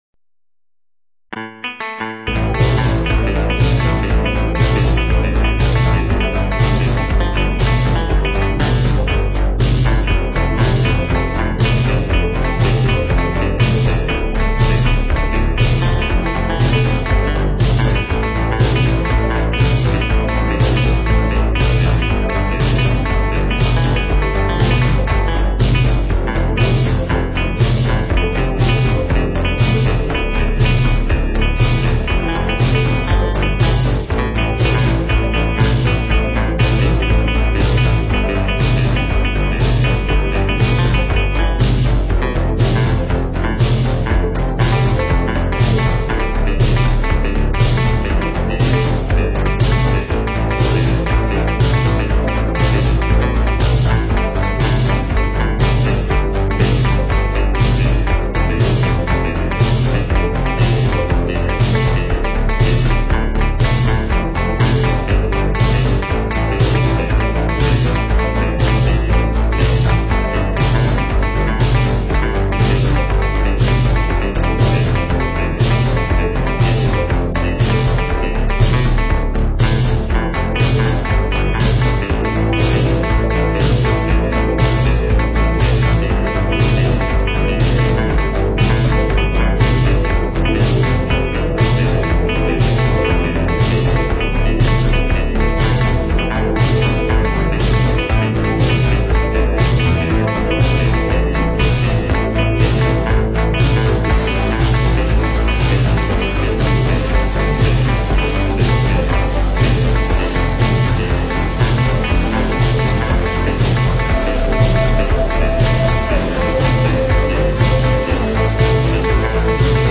Первая полноформатная студийка